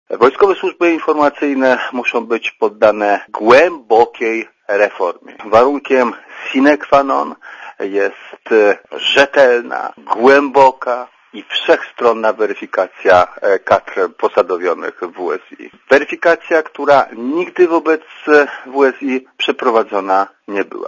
Dla Radia ZET mówi Konstanty Miodowicz (81 KB)